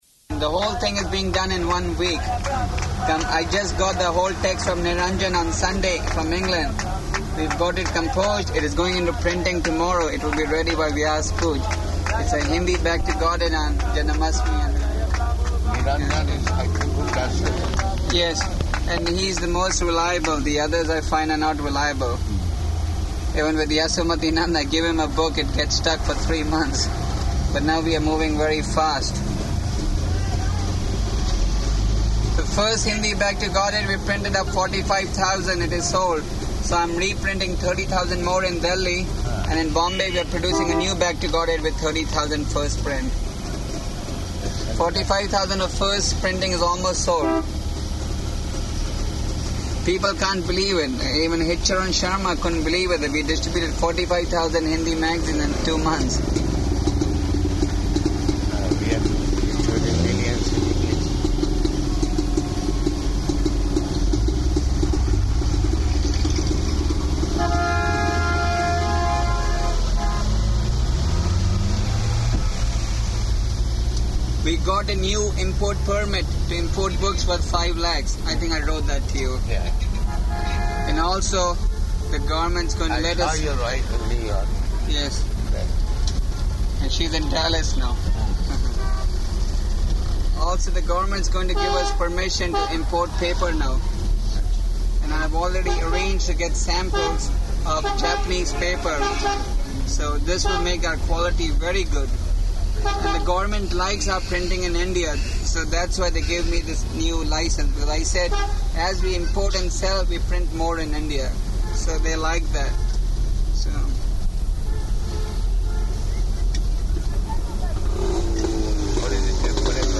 Arrival Conversation in Car and at Temple